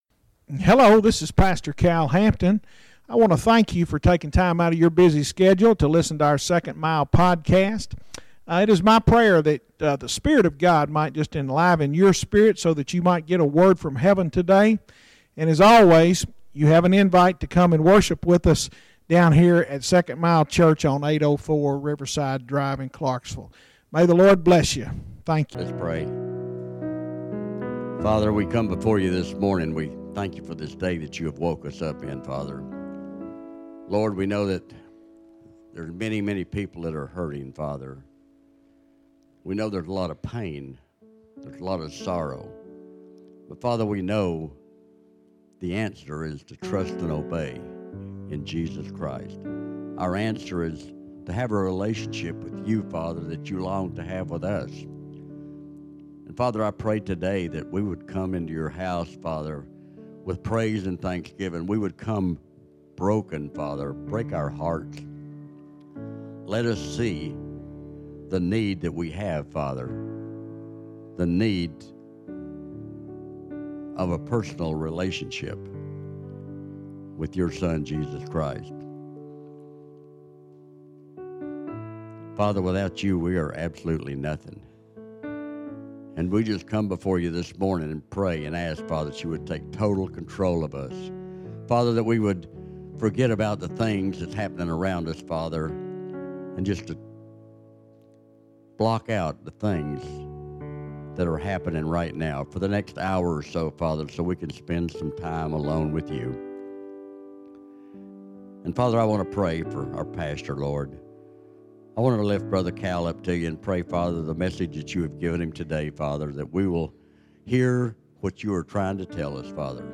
Our Freedom to Love is Described – 5:13-14 We are beginning a 5 part sermon series “Free to Love.”